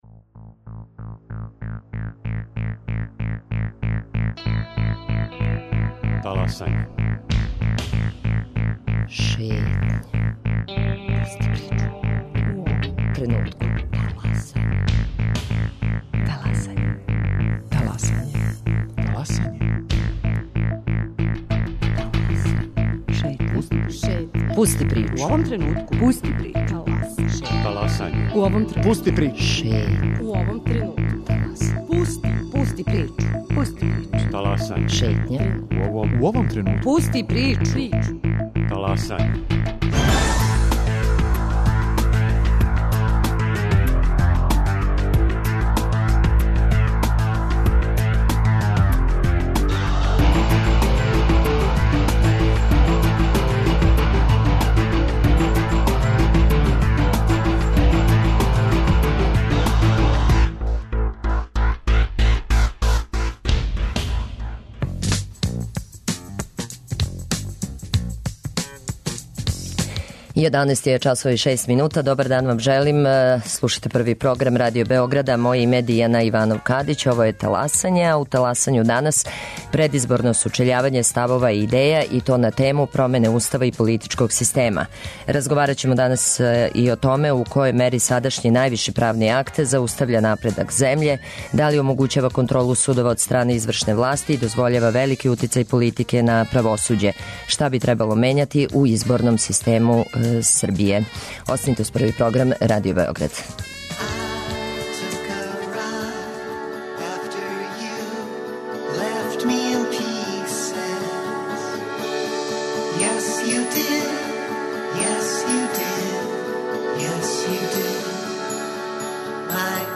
Предизборно сучељавање ставова и идеја на тему промене Устава и политичког система.